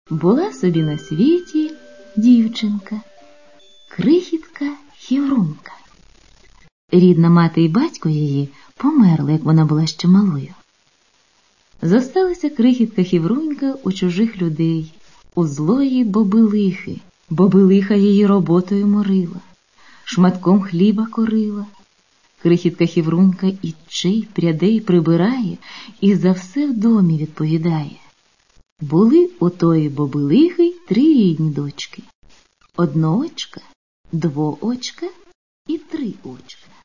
Многим из этих аудио-постановок на сегодняшний день – уже не один десяток лет (разве же не интересно – послушать сейчас запись, например, 1948 года?). И, знаете, звучат они очень даже ярко. Собственно говоря, не все современные аналоги подобных записей достигают того же высокого уровня, как эти – во всяком случае, если речь идет о мастерстве исполнения, актерском искусстве. Между прочим, наверное, Вам приятно будет услышать здесь голоса актеров, которые сегодня составляют славу и гордость украинской культуры – как вот, например, Богдан Ступка.